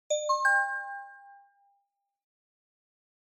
速報 (着信音無料)
News-Alert04-1.mp3